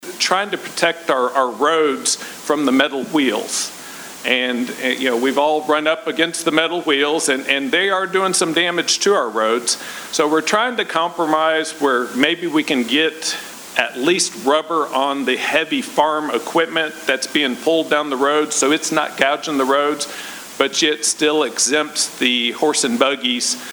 8th District State Representative Walker Thomas, 9th District State Representative Myron Dossett, and 3rd District State Senator Craig Richardson shared some of those Thursday night at a Town Hall.